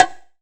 1ST-BONG1 -R.wav